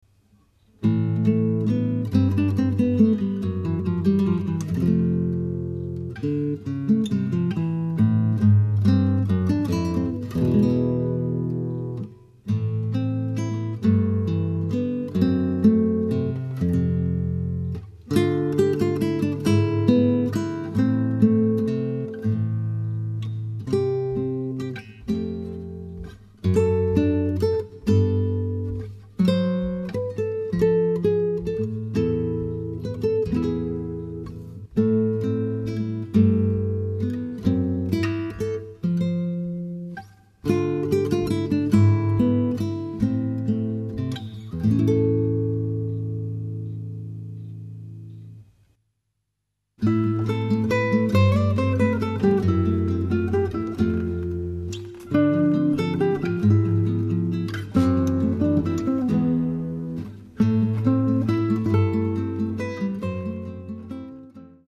ギターデュオ